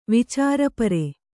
♪ vicārapare